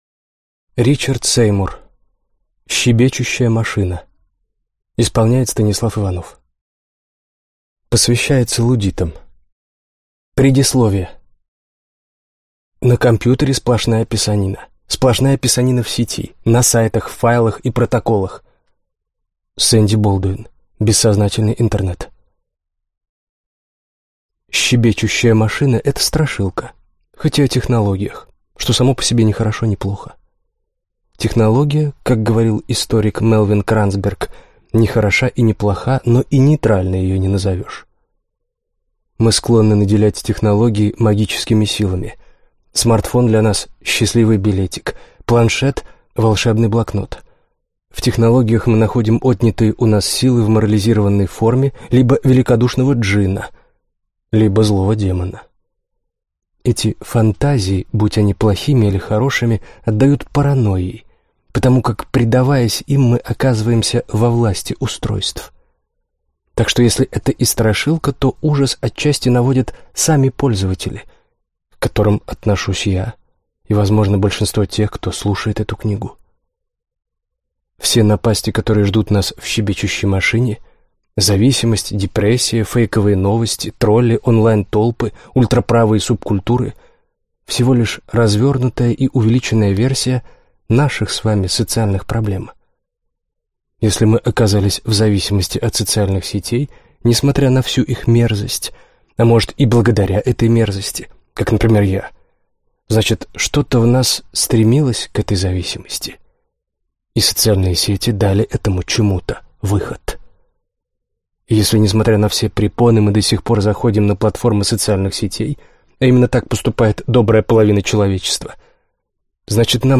Аудиокнига Щебечущая машина | Библиотека аудиокниг
Прослушать и бесплатно скачать фрагмент аудиокниги